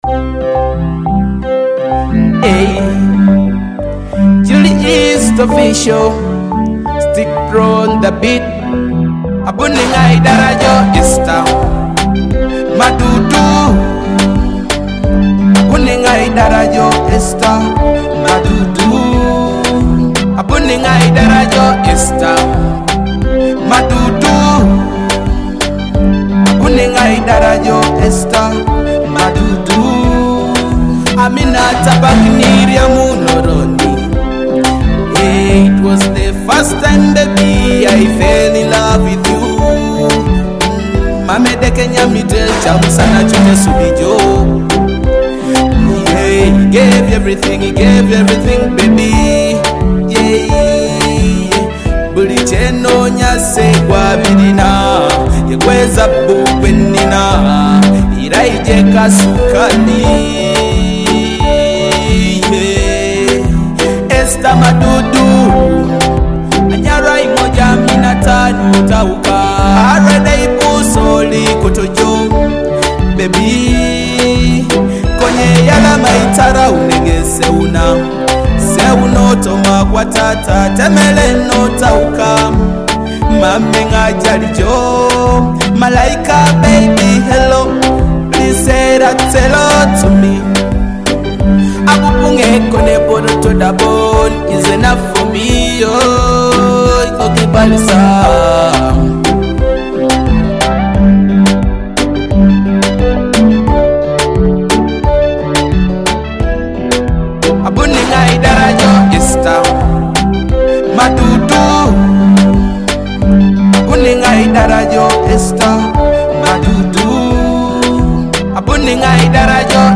a fresh Teso hit